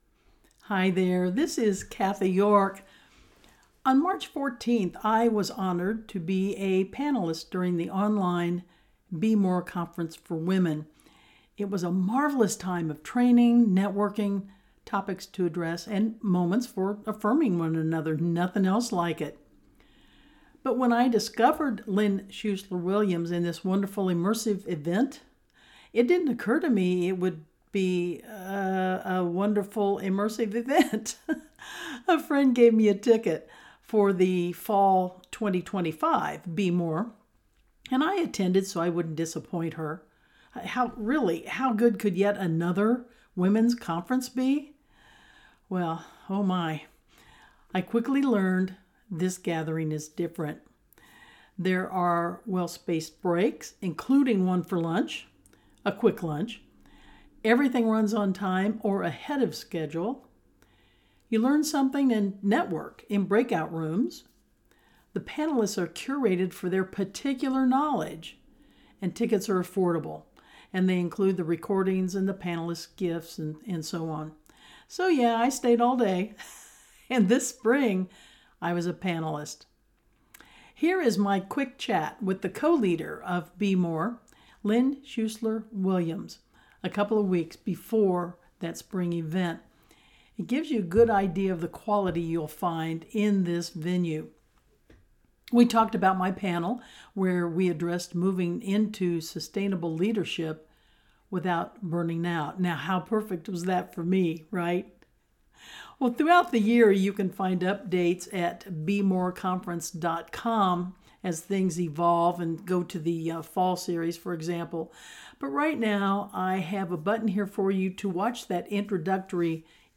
2-minute introductory interview
Guest-Appearance-BeMore-Spring-2026-panelist-interview-article-AUDIO.mp3